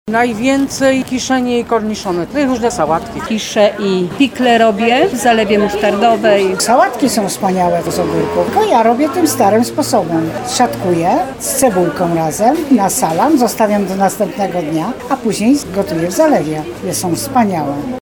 Ogórek jest popularnym produktem do robienia przetworów. Zapytaliśmy rolników handlujących na rynku do czego wykorzystują tegoroczne ogórki.